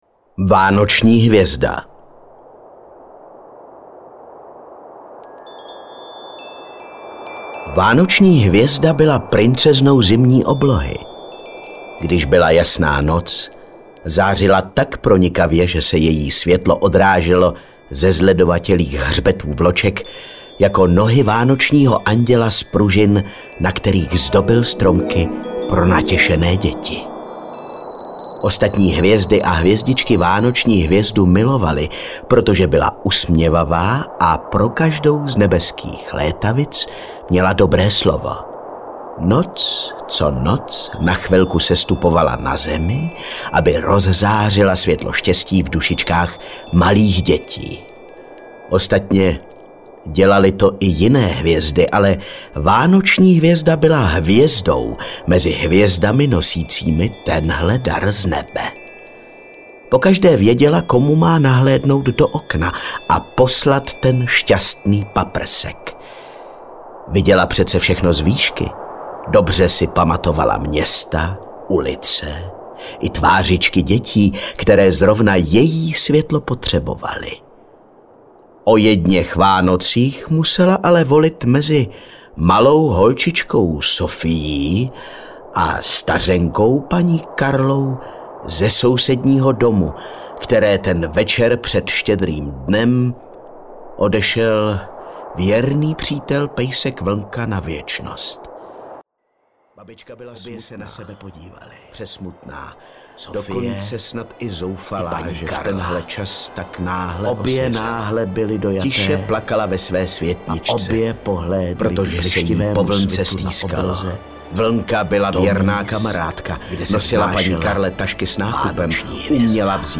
Zimní pohádky audiokniha
Ukázka z knihy
zimni-pohadky-audiokniha